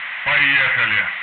radio go3 01